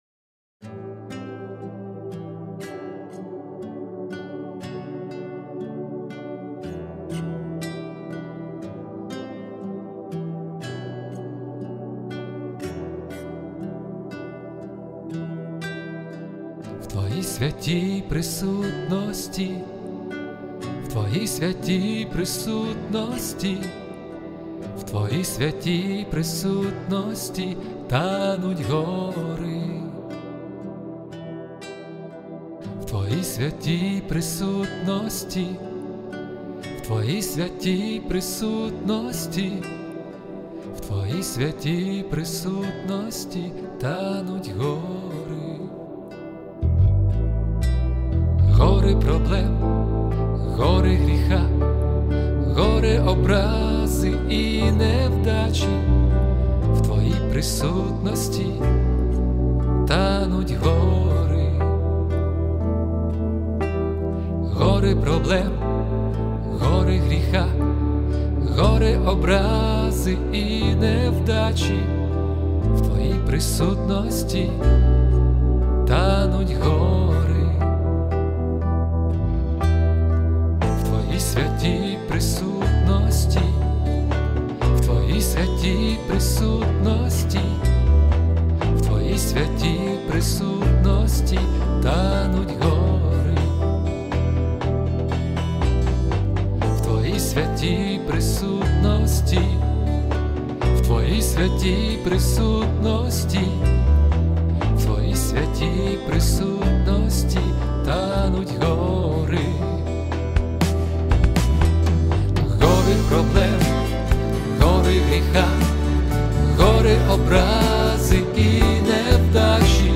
145 просмотров 199 прослушиваний 23 скачивания BPM: 60